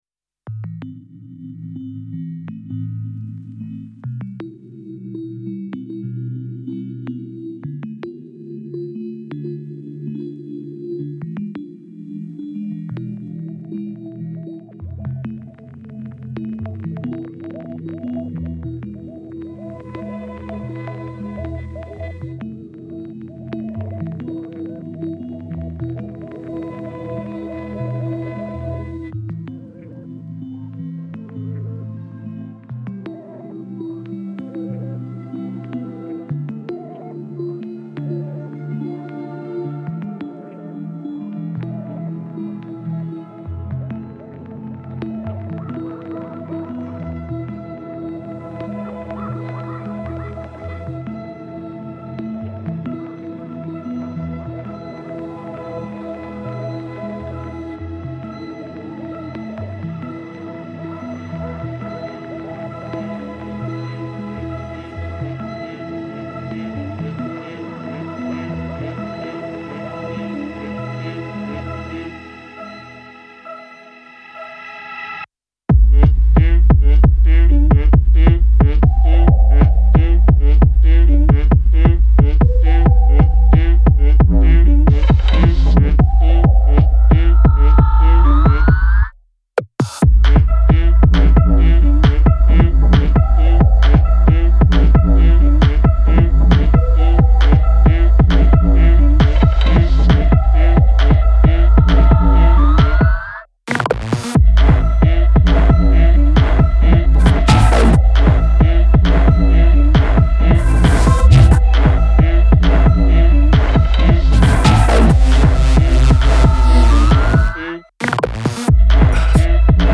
It’s an unpretentiously inky and aquatic piece.